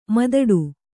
♪ madaḍu